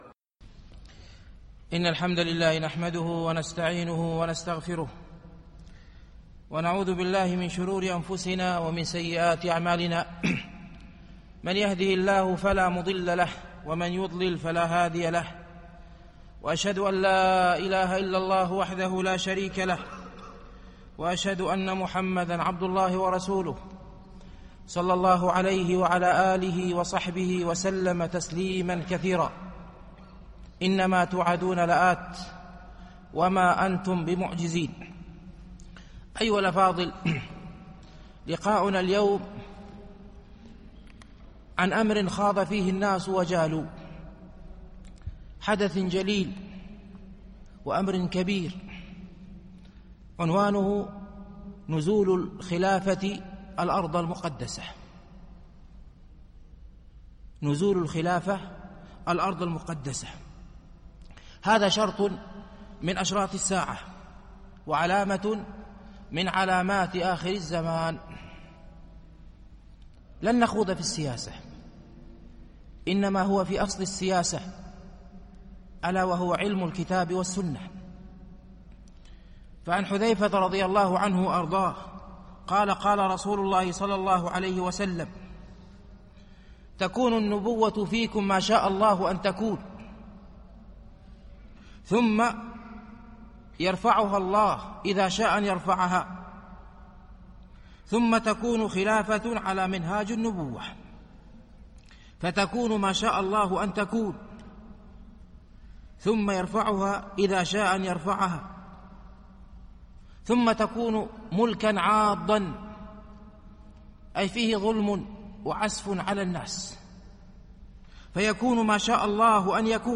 الألبوم: محاضرات